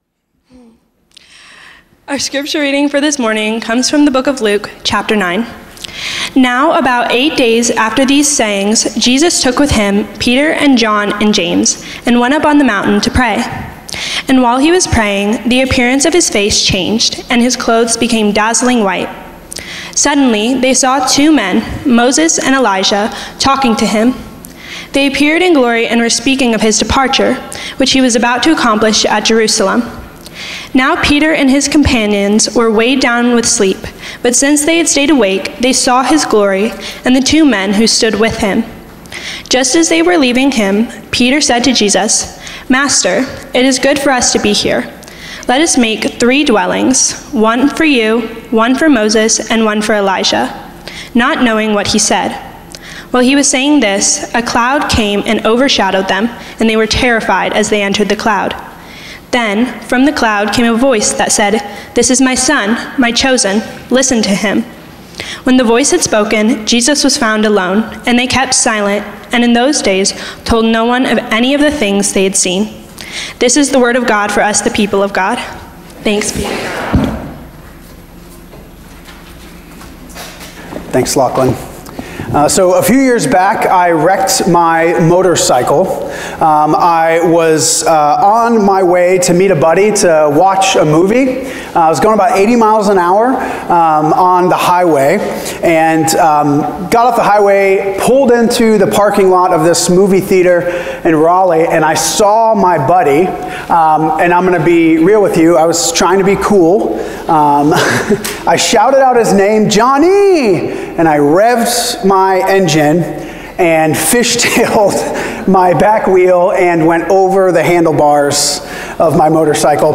First Cary UMC's First on Chatham Sermon